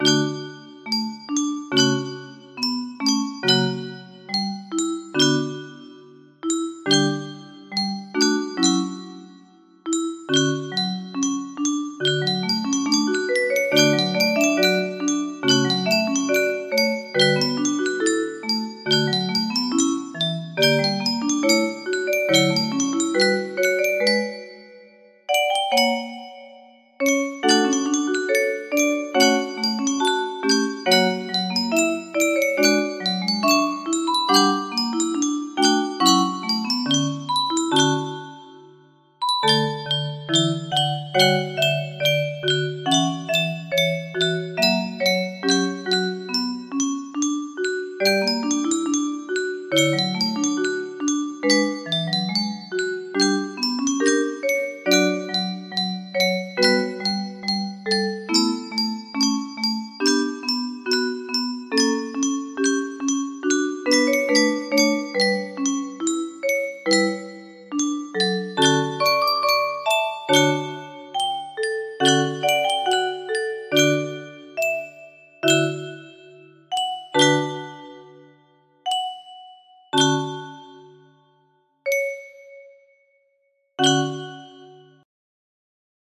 music box melody
Full range 60